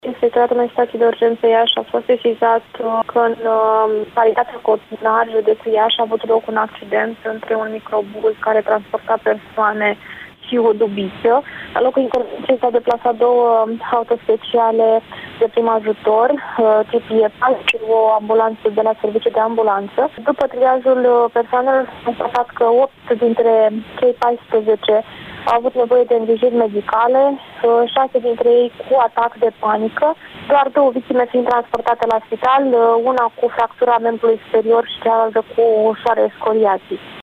Într-o declarație acordată postului nostru de radio